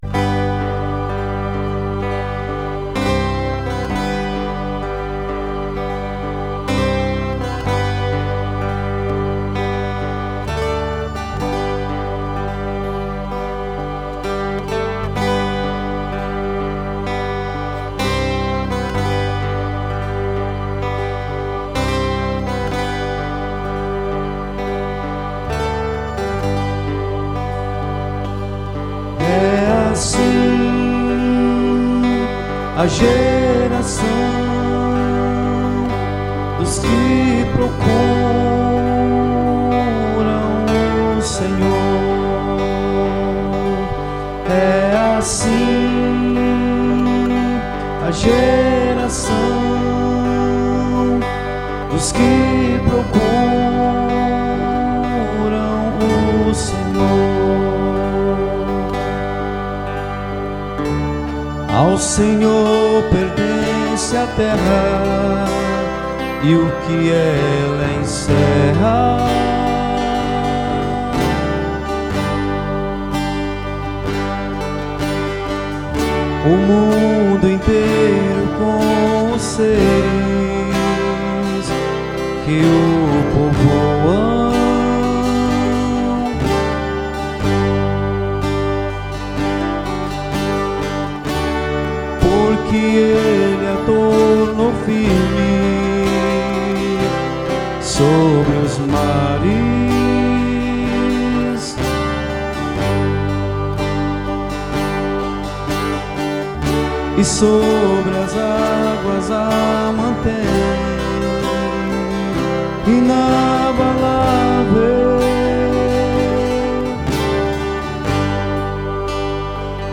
EstiloCatólica